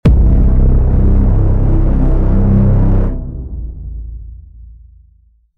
Download Trailer sound effect for free.
Trailer